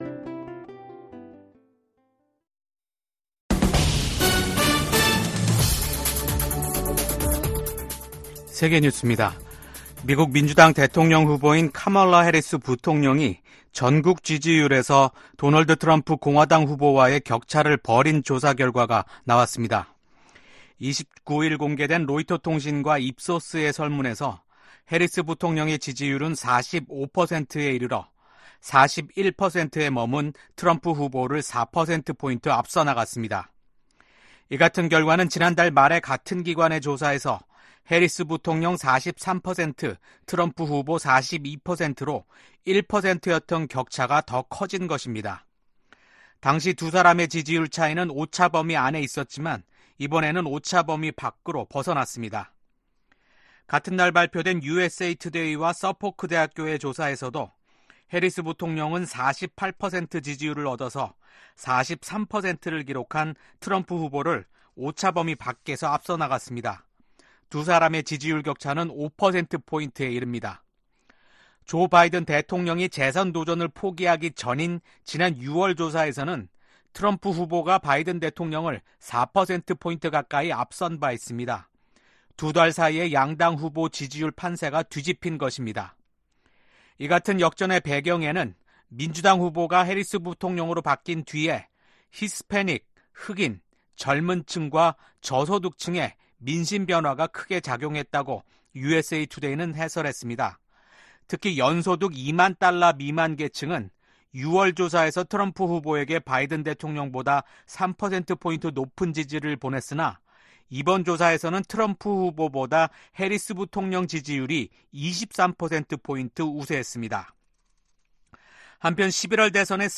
VOA 한국어 아침 뉴스 프로그램 '워싱턴 뉴스 광장' 2024년 8월 31일 방송입니다. 북한 해군 자산들이 국제해사기구(IMO) 자료에서 사라지고 있습니다.